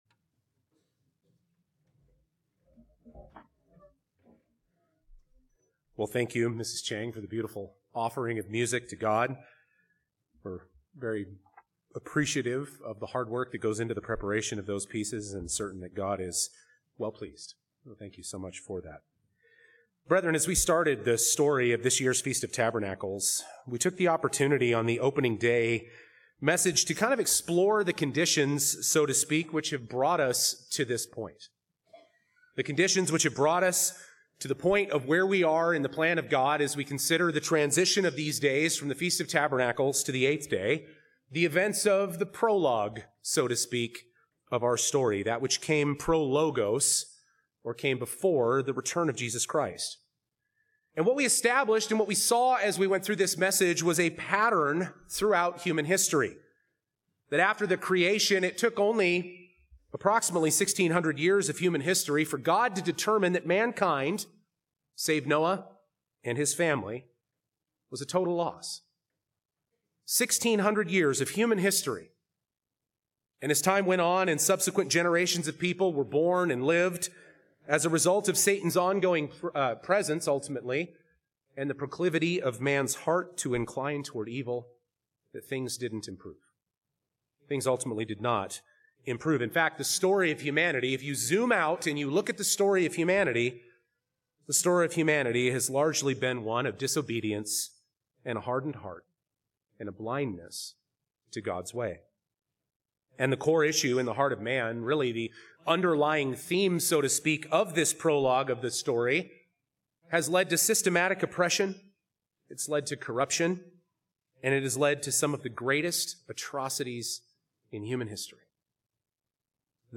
Sermon - 8th Day AM - Feast of Tabernacles - Klamath Falls, Oregon 2024
This sermon was given at the Klamath Falls, Oregon 2024 Feast site.